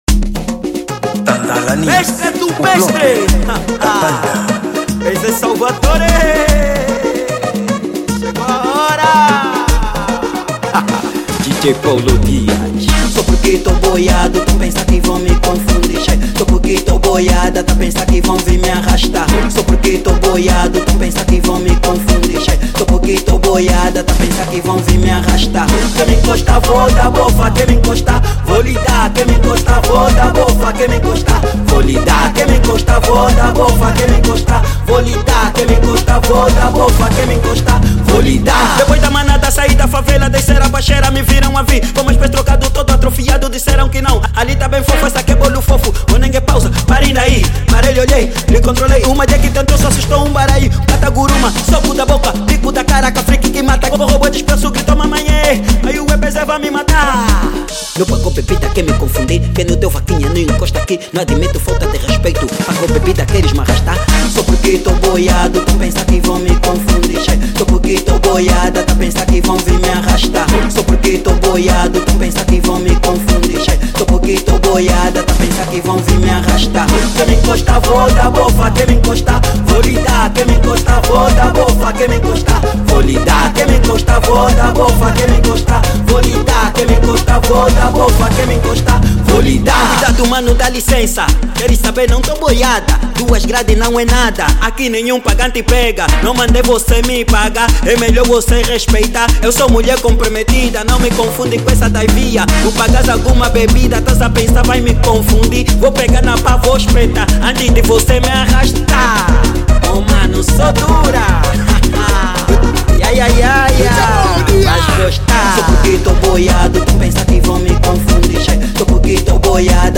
Gênero: Kuduro